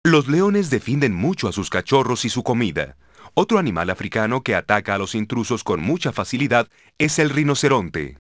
LION05NJ.WAV